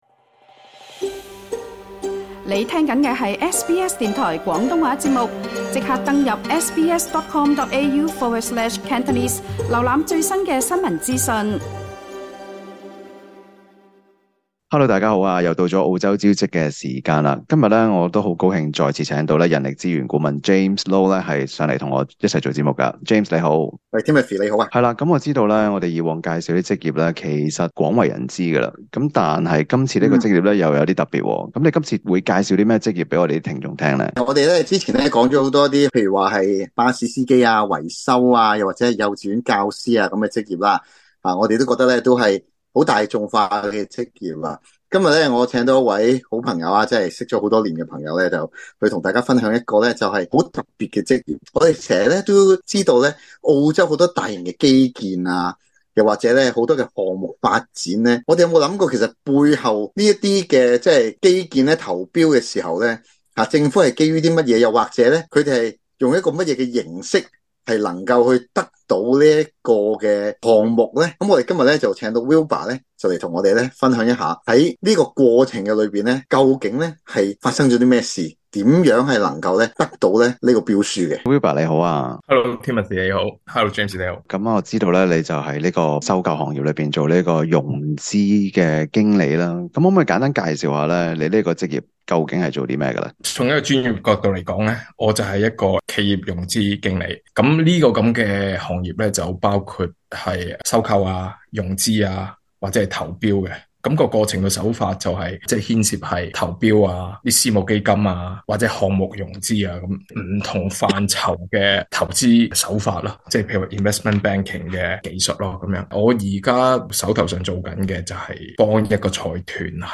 就請來一位在澳洲從事收購行業的企業融資經理，與大家分享這個行業究竟是做些甚麼，以及如何入行。